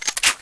Index of /fastdl/cstrike/sound/weapons
p90_magout.wav